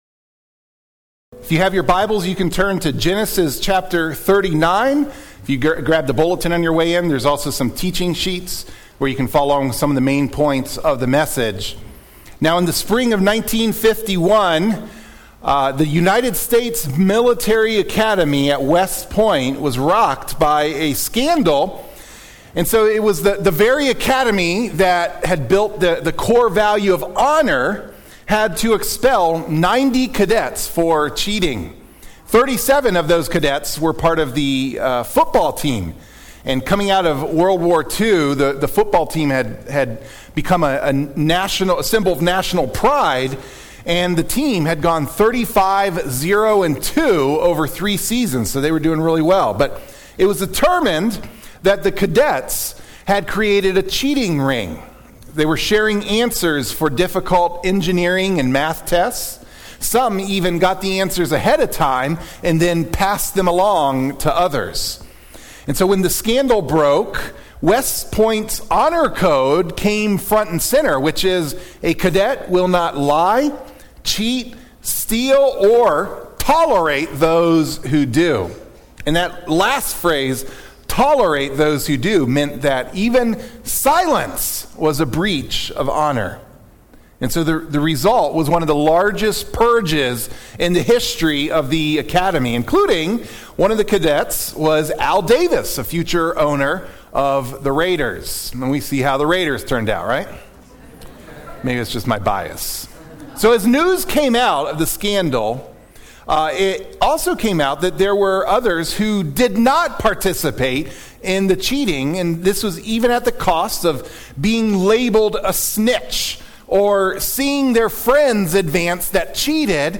Genesis 39 - Joseph and Temptation - Island Pond Baptist Church How to Resist Temptation Like Joseph (Genesis 39 Sermon)